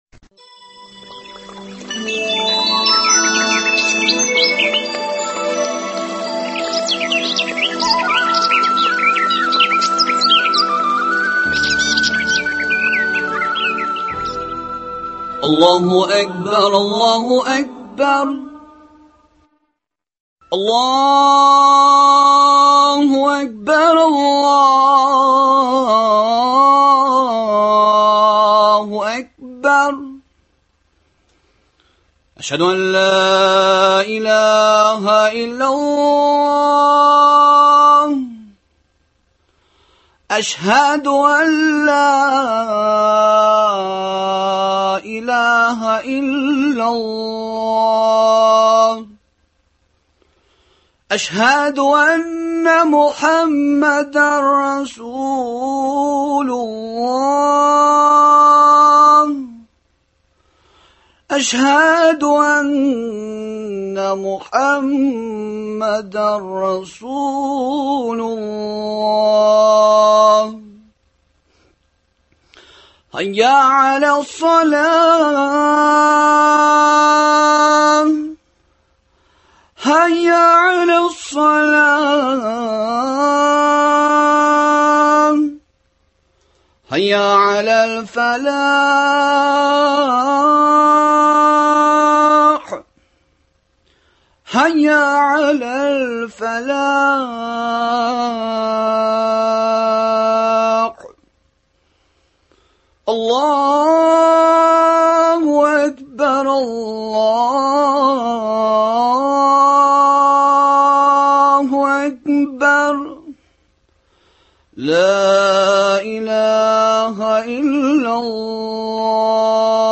әңгәмә. Мөселман кешесе Яңа ел бәйрәменә һәм аның атрибутларына нинди мөнәсәбәттә булырга тиеш? Дөньяви бәйрәмнәр мөселман кешесенә кирәкме һәм ни өчен кирәк?